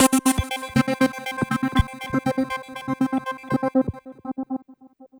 SYNTH CLO0FL.wav